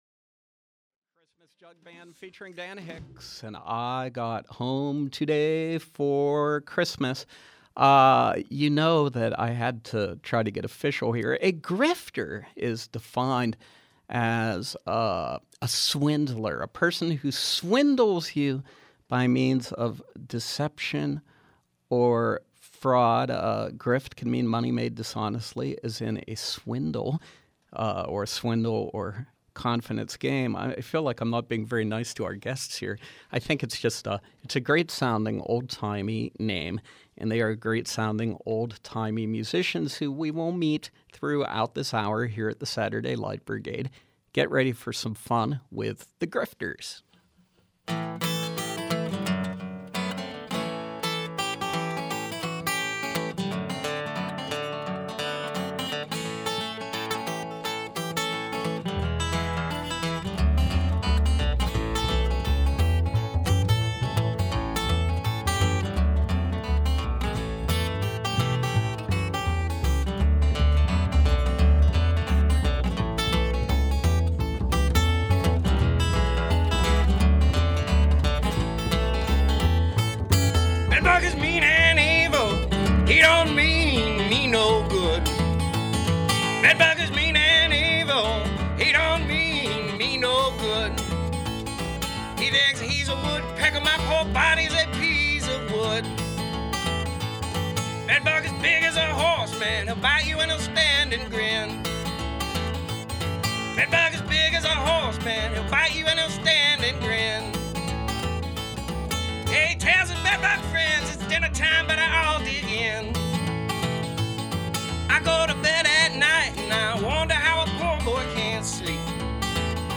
Old-time folk and blues tunes